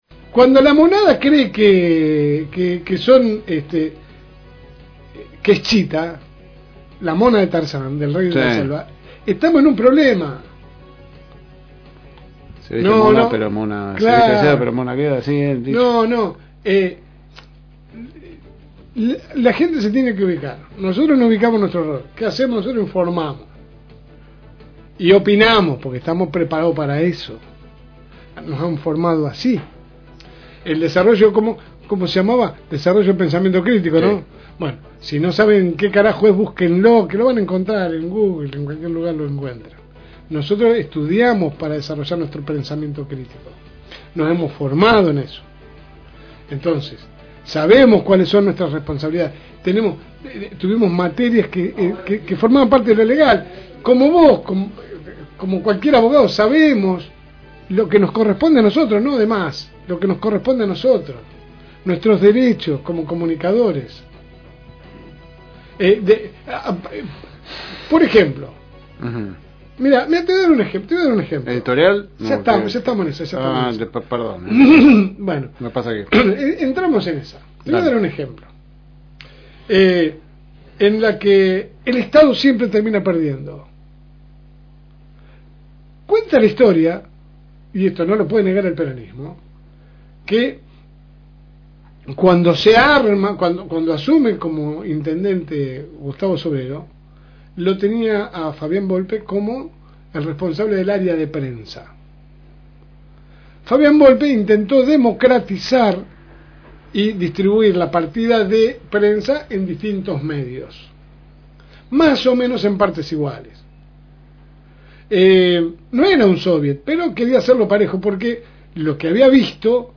AUDIO – Editorial de La Segunda Mañana – FM Reencuentro